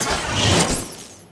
attk 1 magic bolt.wav